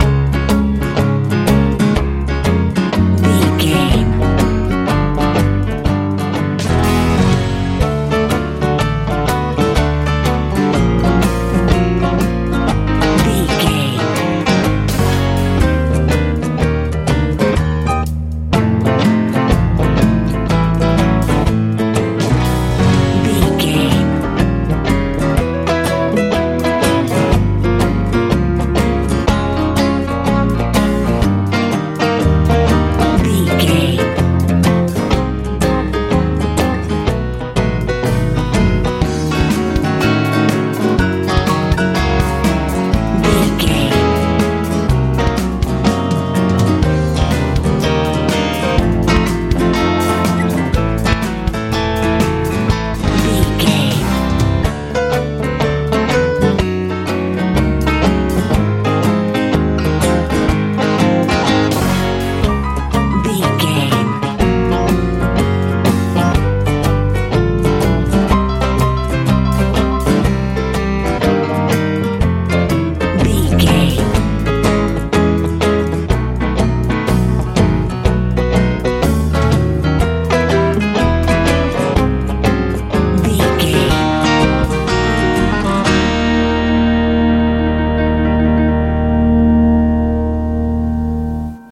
country boogie feel
Ionian/Major
C♯
light
drums
bass guitar
acoustic guitar
electric guitar
piano
southern
playful